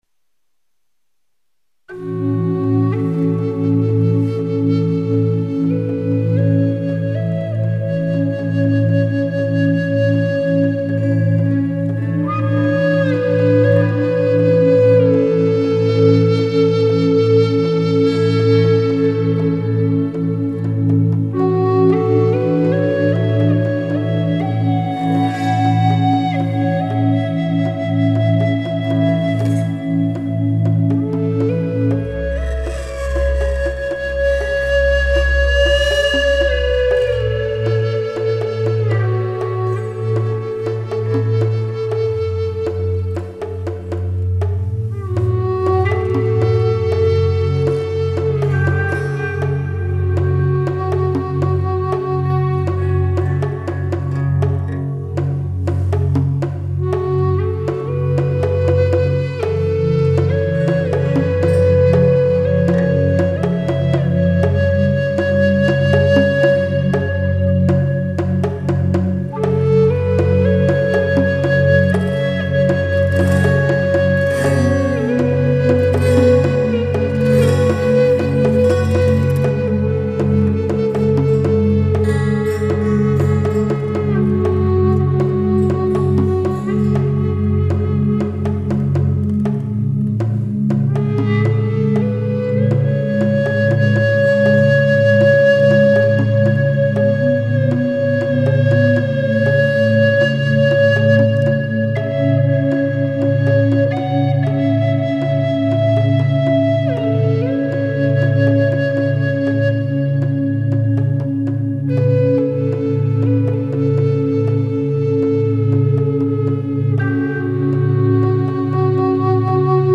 MyNewAgeFluteComposition.mp3